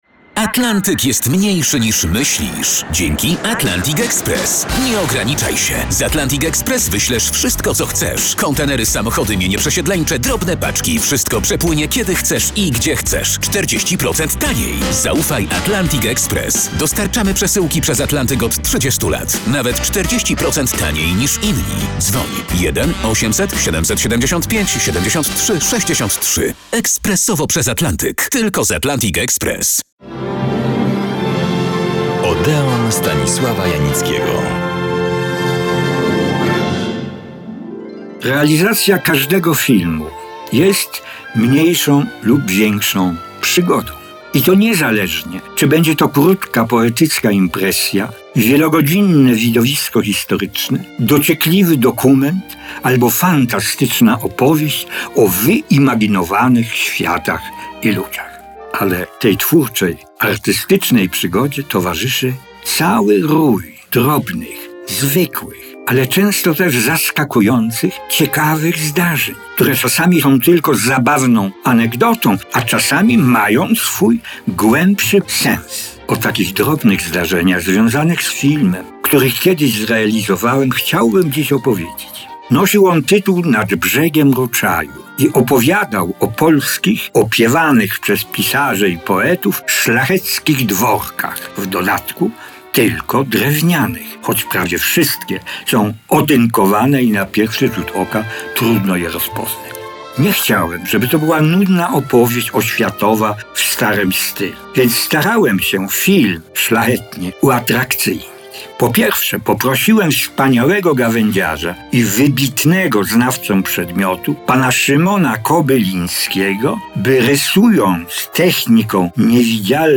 Rozmowa Artura Andrusa z Justyną Sieńczyłło - 17.11.2024